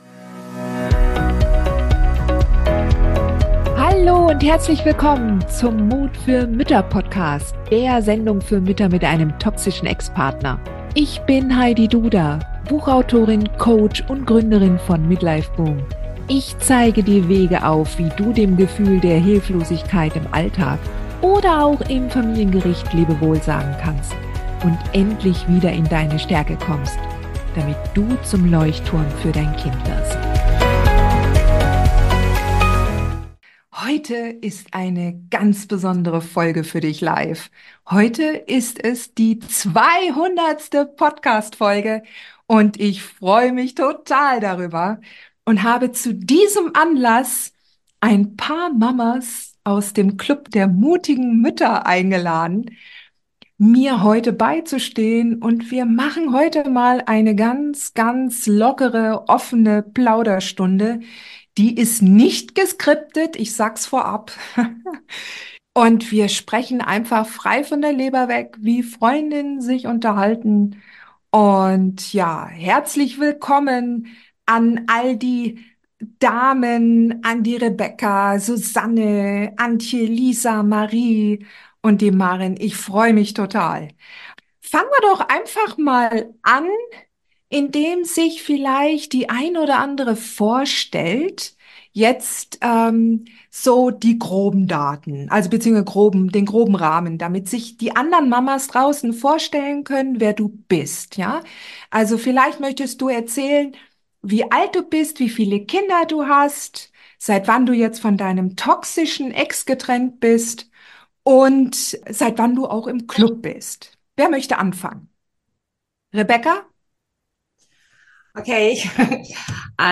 Zu diesem Anlass habe ich einige wunderbare Mitglieder aus unserem "Club der mutigen Mütter" eingeladen, die ihre beeindruckenden Geschichten und Erfahrungen mit uns teilen. In einer entspannten Atmosphäre sprechen wir darüber, wie lange sie schon im Club sind, welche Fortschritte sie gemacht haben und wie ihnen unsere Gemeinschaft geholfen hat, die Herausforderungen des Lebens mit einem toxischen Ex-Partner zu meistern.